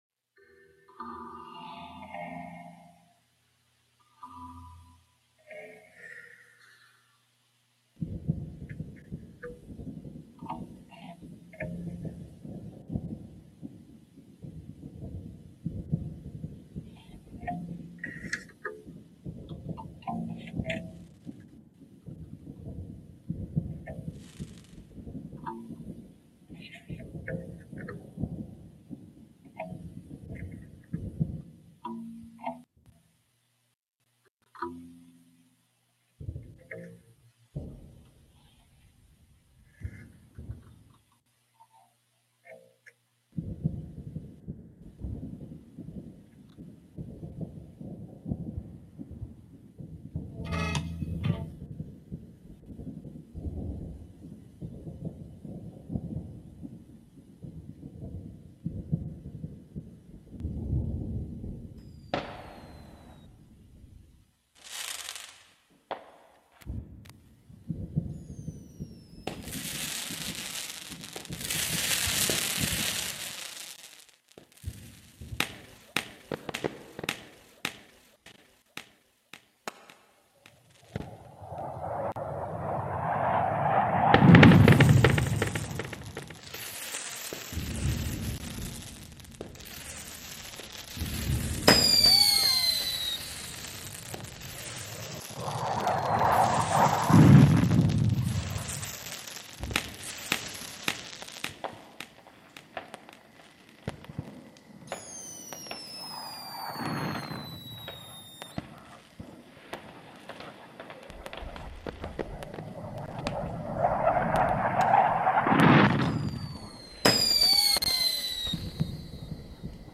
The Conduction Series is a collaborative live radio broadcast produced by sound and transmission artists across the Americas on Wave Farm’s WGXC 90.7-FM Radio for Open Ears in New York’s Upper Hudson Valley.
Emphasizing live radio, interactivity, and media archaeological methods, the series explores themes of migration, noise, feedback, speculative fiction, transmissive theatrics, and recreational aesthetics.